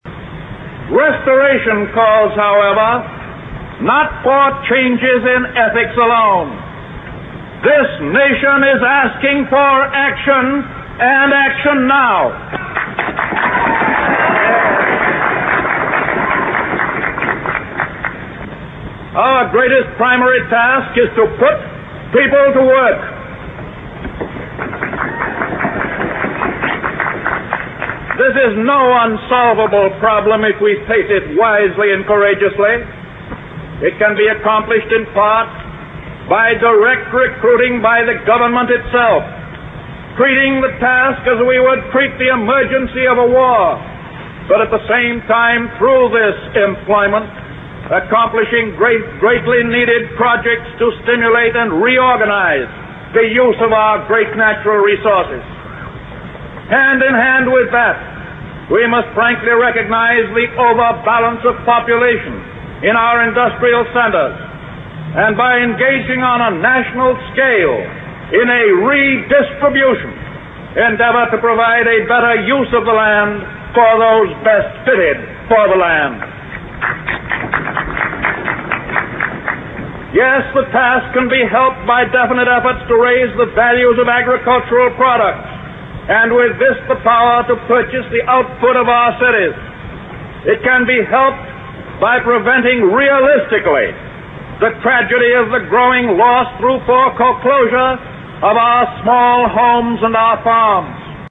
名人励志英语演讲 第20期:我们唯一害怕的是害怕本身(5) 听力文件下载—在线英语听力室